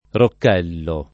rocchello [ rokk $ llo ] s. m.